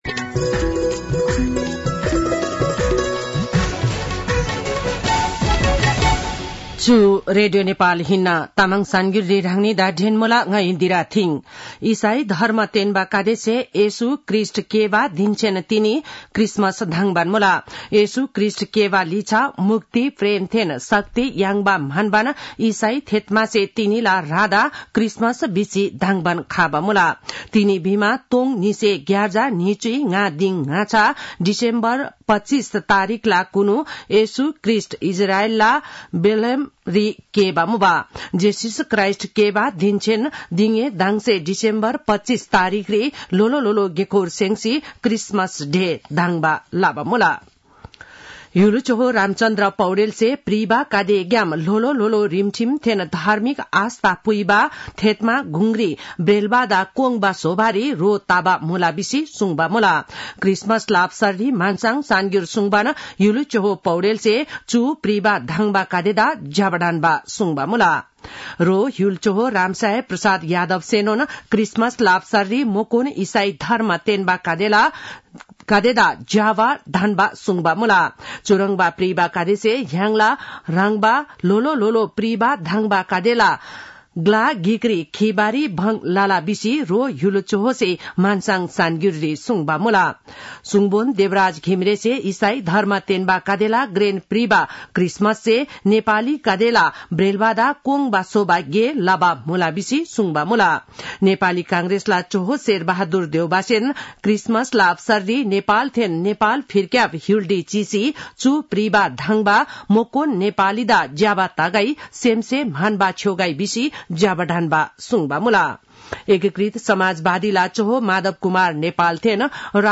तामाङ भाषाको समाचार : ११ पुष , २०८१
Tamang-News-9-10.mp3